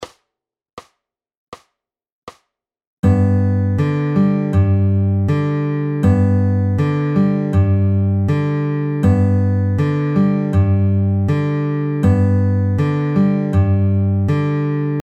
Here we add a note that is played “in between” bass notes.
ADD AN IN-BETWEEN NOTE